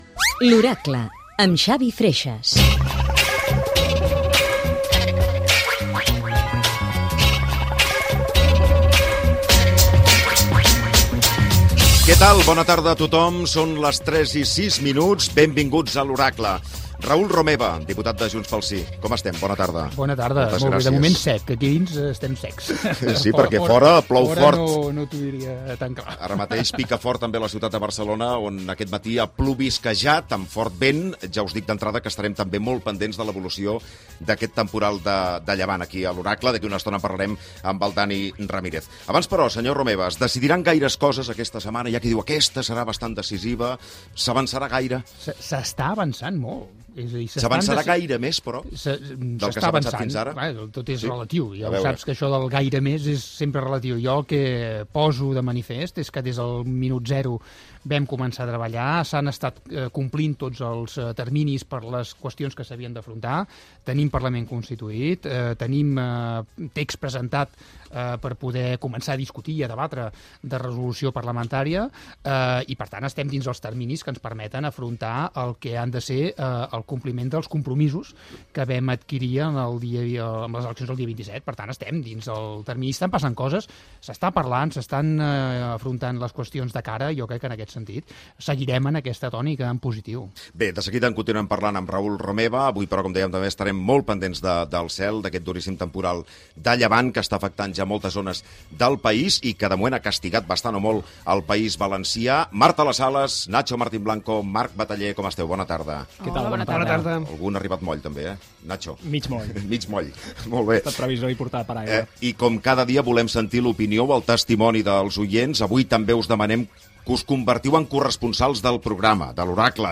Careta del programa
Tertúlia política amb l'inivitat